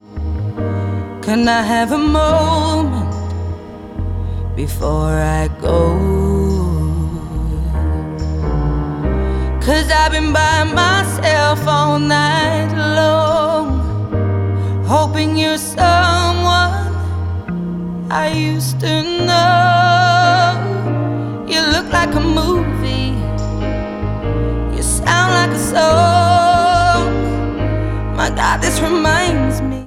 • Pop
is a soul ballad